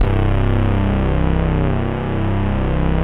SD1 FAT BR.wav